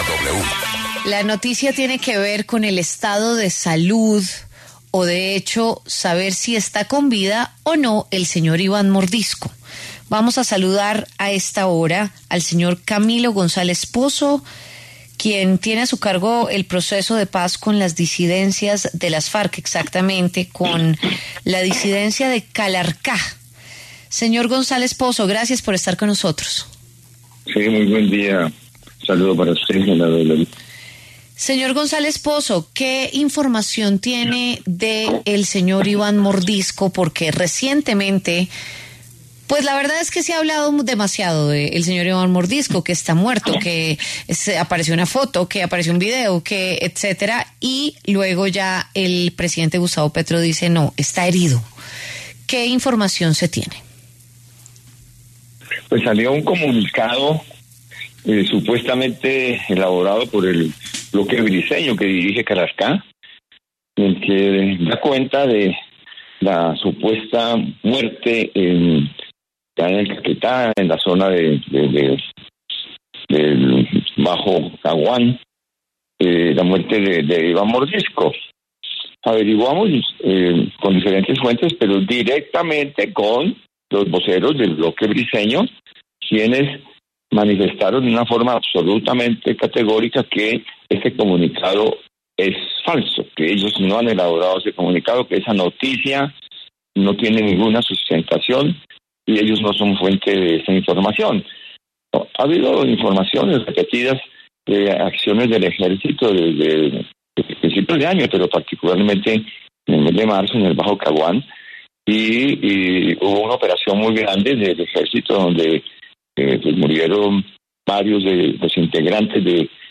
Camilo González Posso, negociador del Gobierno con las disidencias de alias ‘Calarcá’, habló en los micrófonos de W Fin de Semana sobre ‘Iván Mordisco’.